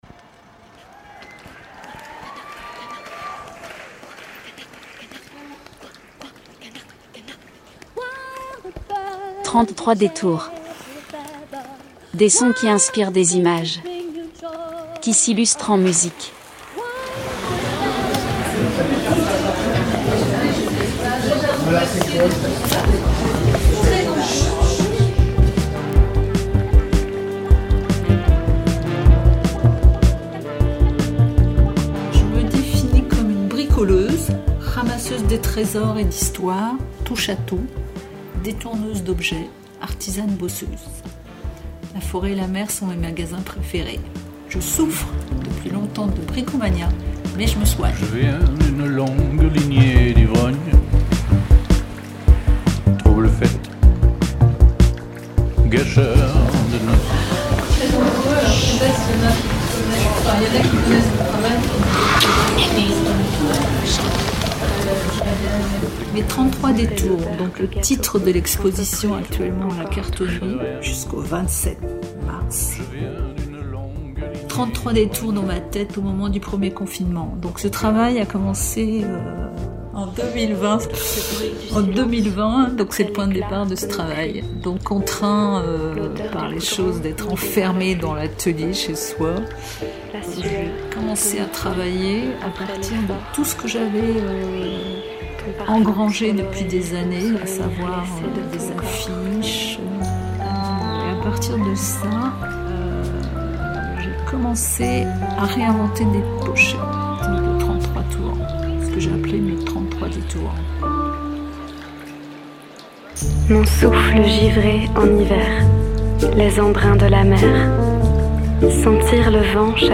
Reportage à la Cartonnerie (9:30)
Radio Primitive s'est rendue au vernissage.